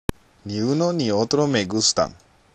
（ニウノ　ニオトロ　メ　グスタン）